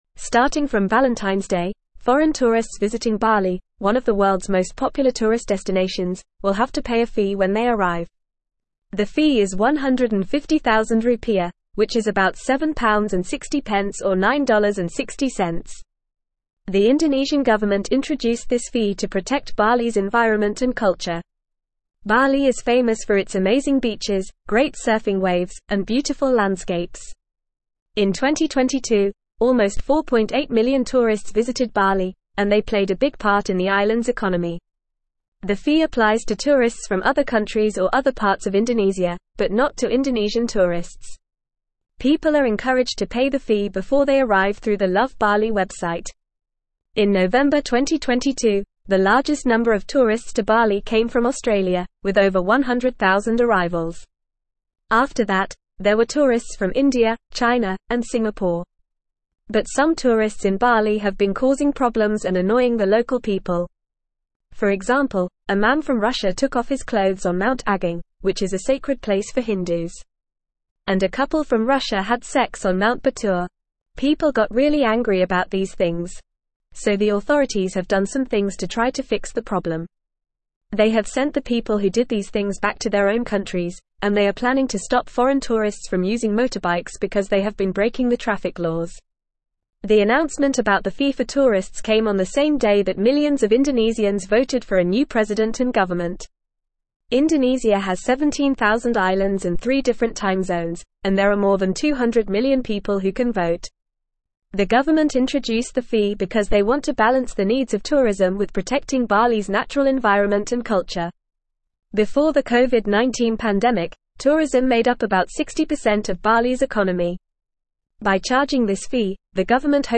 Fast
English-Newsroom-Upper-Intermediate-FAST-Reading-Bali-Implements-Tourist-Levy-to-Protect-Environment-and-Culture.mp3